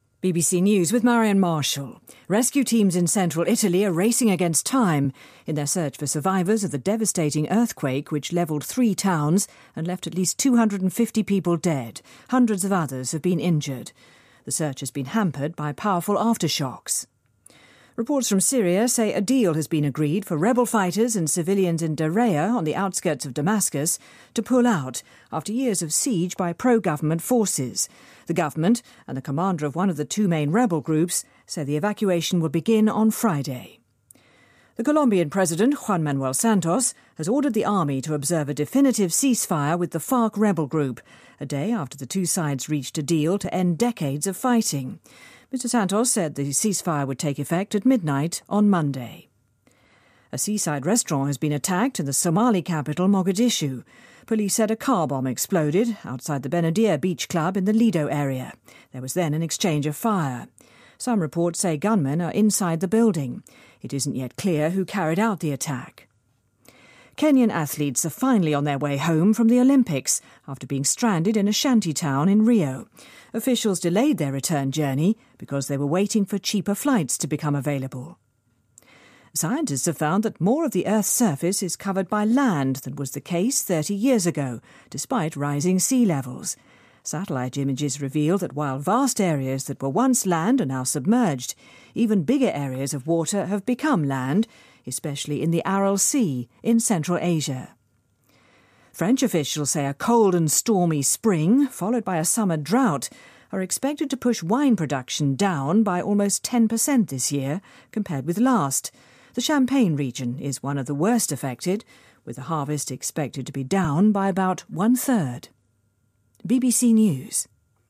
BBC news,意大利发生强震 三座城镇被夷为平地